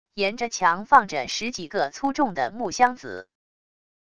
沿着墙放着十几个粗重的木箱子wav音频生成系统WAV Audio Player